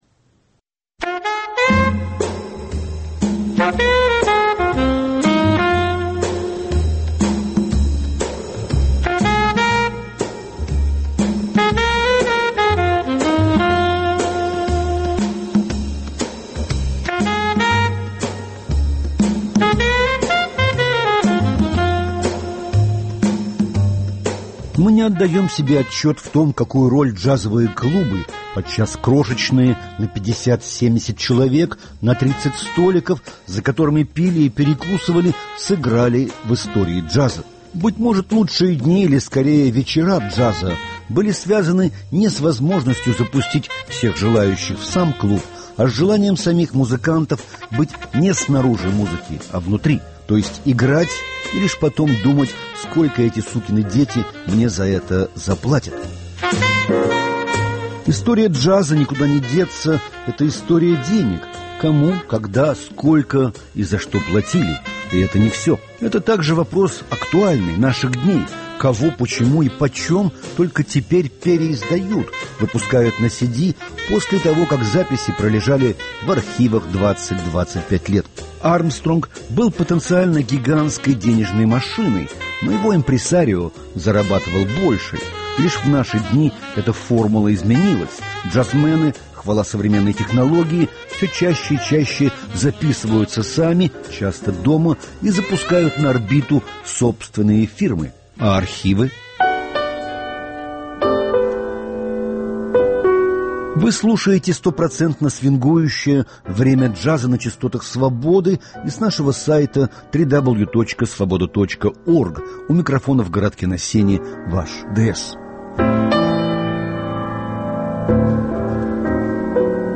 В «Keystone Korner» было записано LIVE немало исторических дисков.